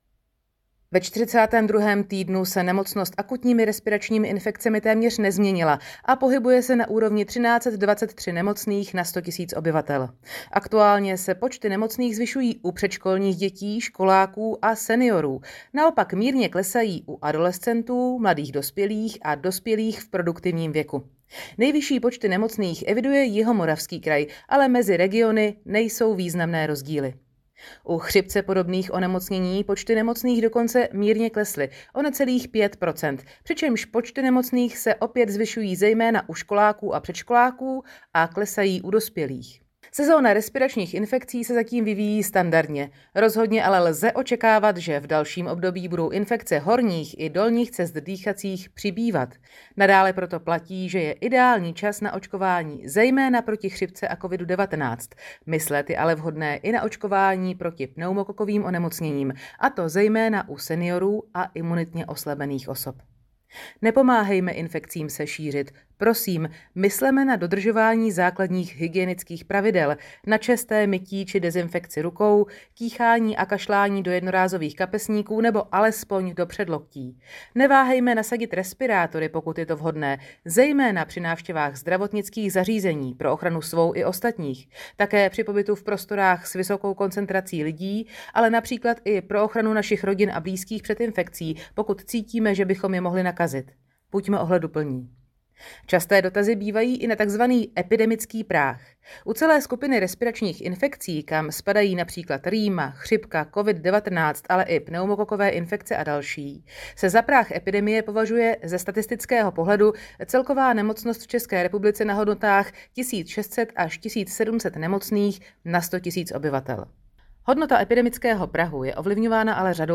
Zvuk k tiskové zprávě ARI/ILI 42. KT